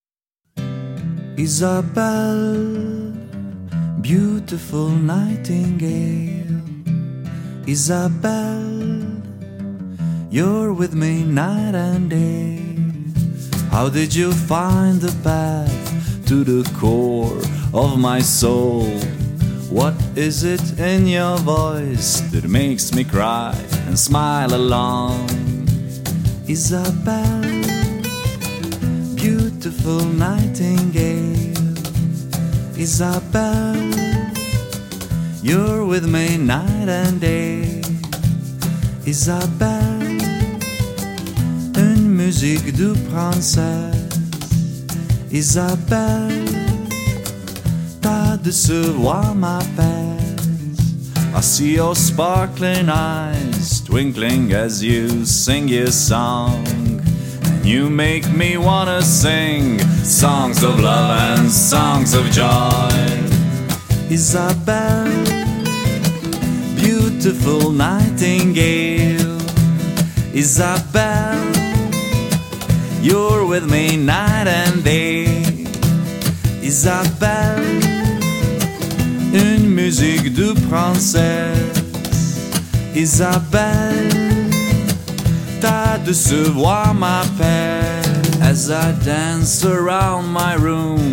acoustic album version